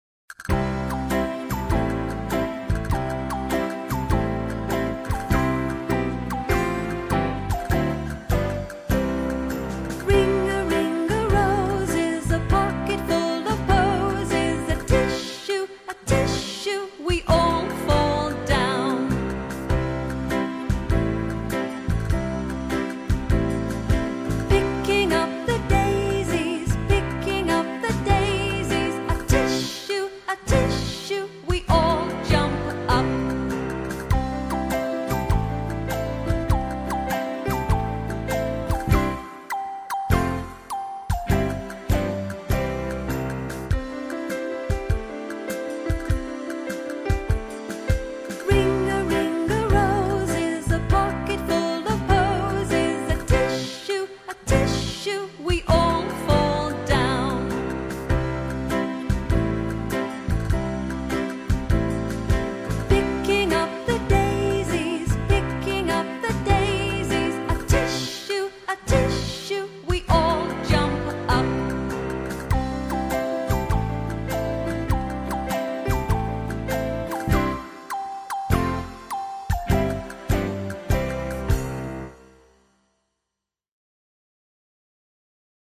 Песни-потешки